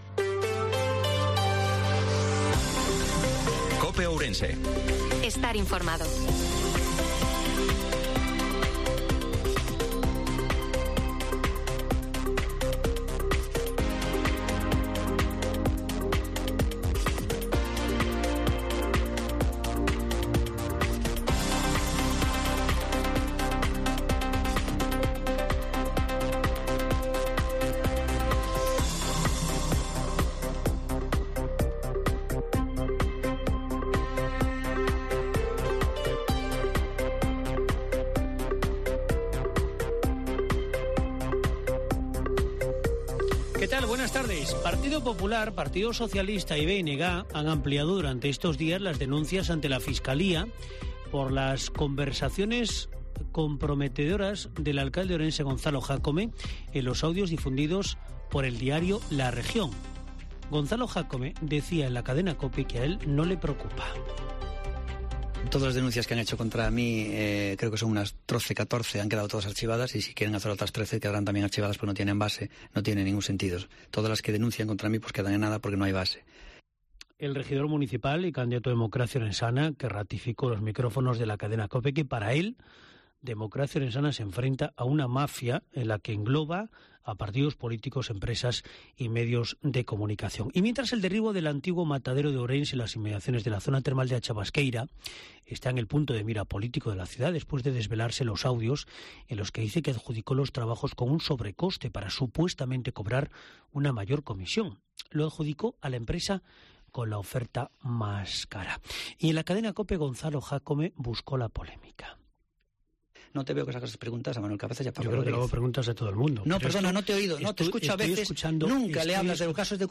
INFORMATIVO MEDIODIA C OPE OURENSE-24/05/2023